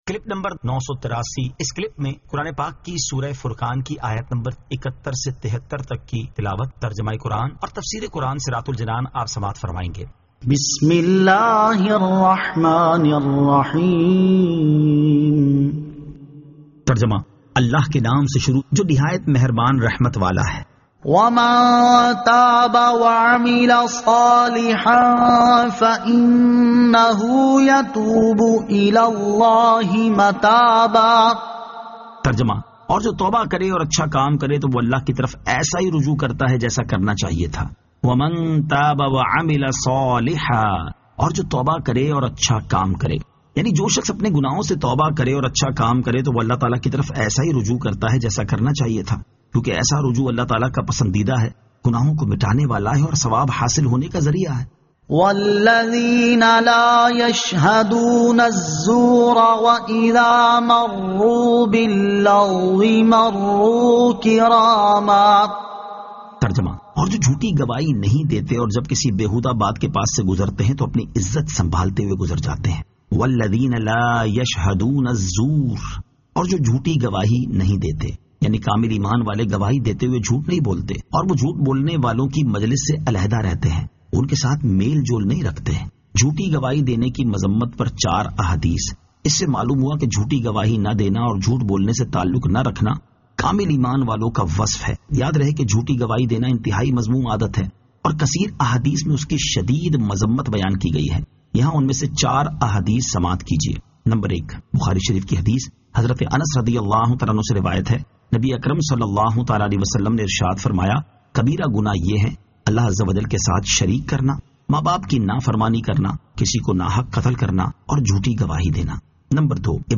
Surah Al-Furqan 71 To 73 Tilawat , Tarjama , Tafseer